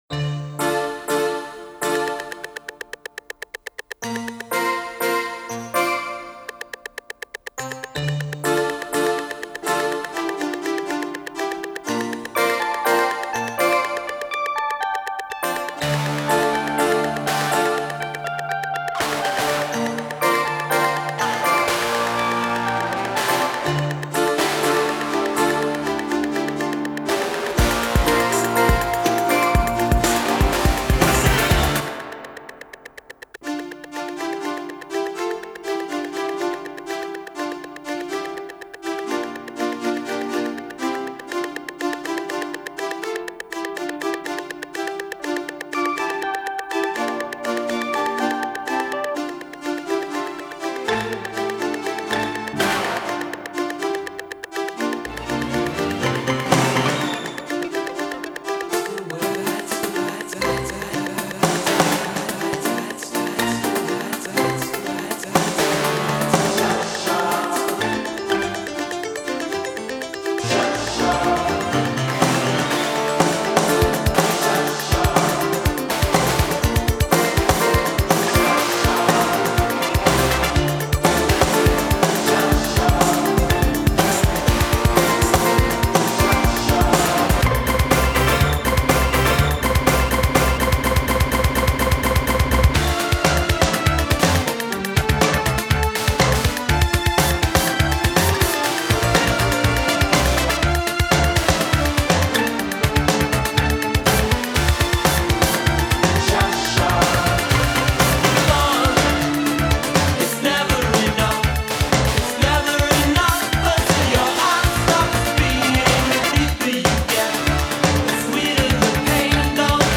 (12 Inch Extended Version)